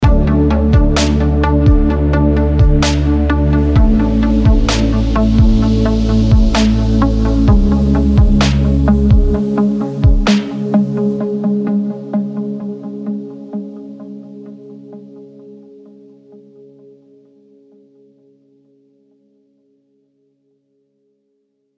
Помогите с pluck'ом
Друзья мои, помогите накрутить такой плак в сайленте.
В чем суть такого плака?